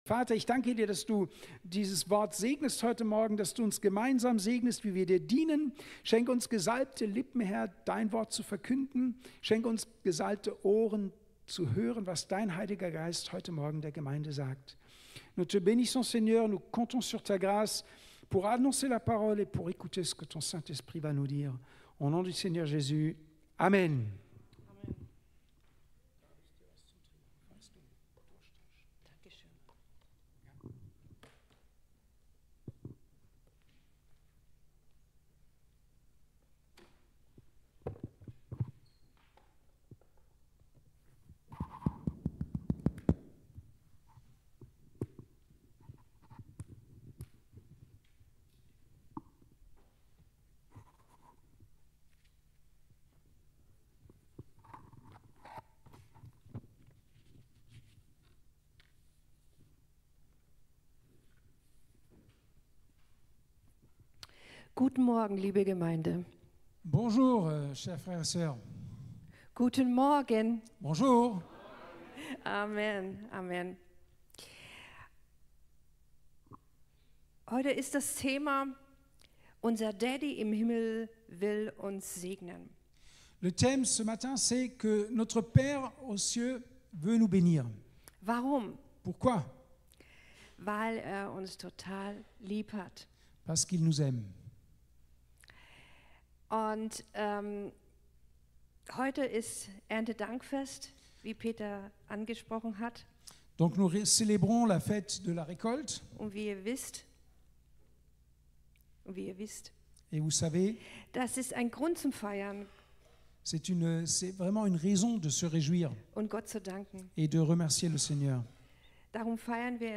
05.10.2025 Ort: Gospelhouse Kehl